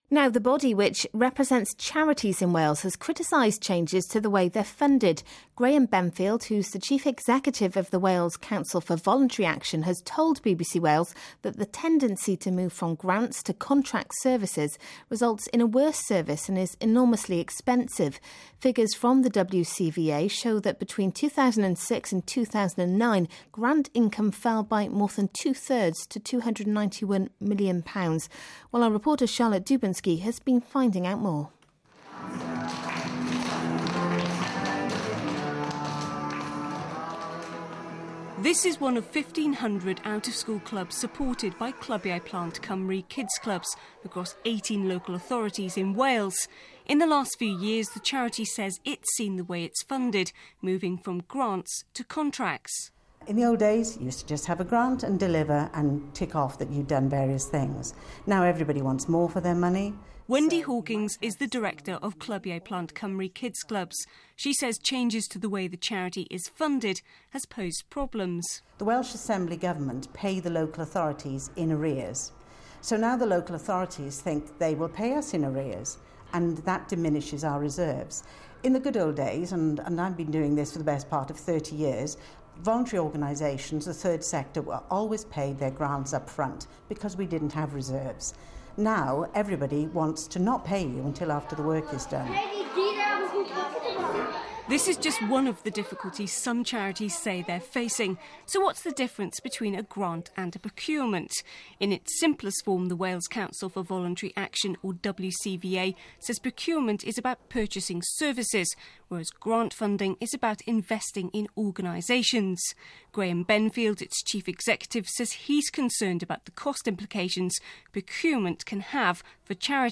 Figures from the WCVA show between 2006 and 2009 grant income fell by more than two thirds to 291 million pounds. Our reporter